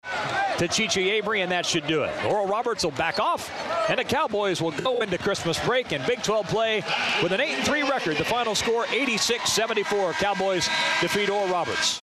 Here was the final call Sunday afternoon on KWON.
x-final call (86-74).mp3